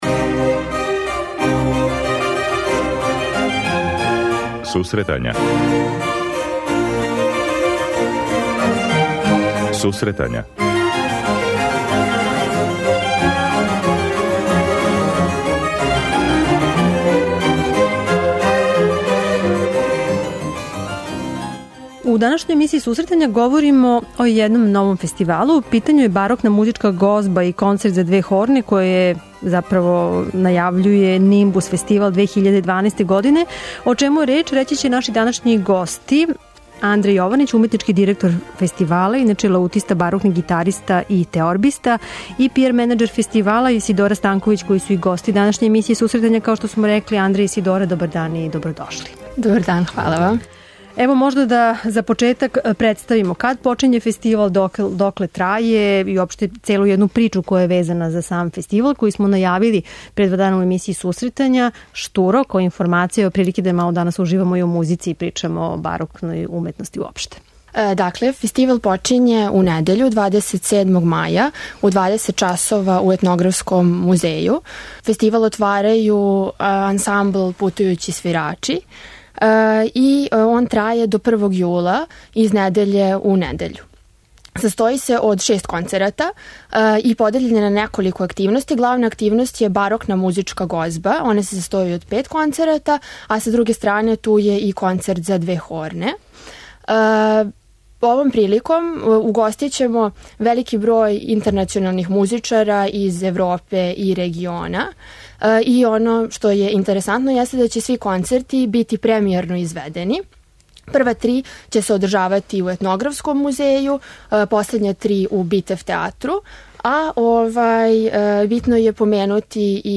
Радио Београд 1, 16.00